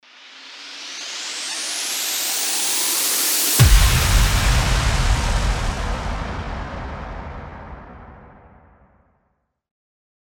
FX-1540-RISING-IMPACT
FX-1540-RISING-IMPACT.mp3